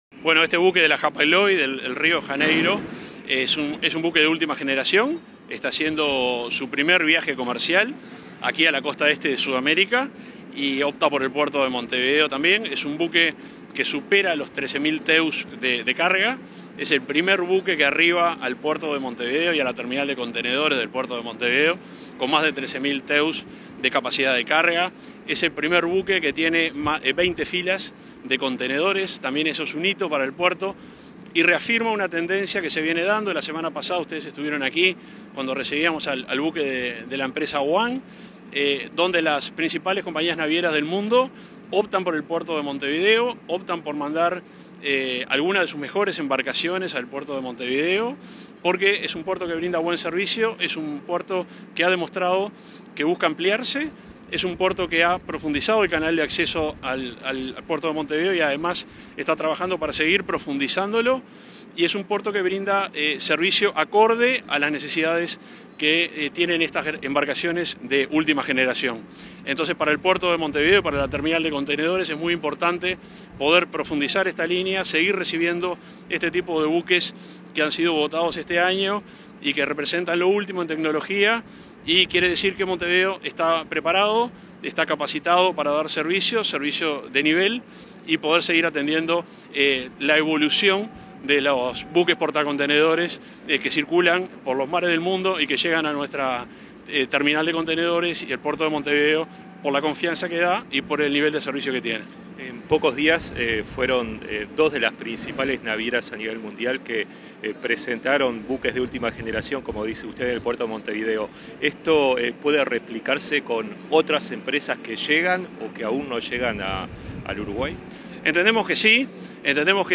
Declaraciones del subsecretario de Transporte y Obras Públicas, Juan José Olaizola
Declaraciones del subsecretario de Transporte y Obras Públicas, Juan José Olaizola 03/11/2022 Compartir Facebook X Copiar enlace WhatsApp LinkedIn Tras la llegada del buque Río de Janeiro Express con capacidad de 13.312 teus, el subsecretario de Transporte y Obras Públicas, Juan José Olaizola, realizó declaraciones a la prensa.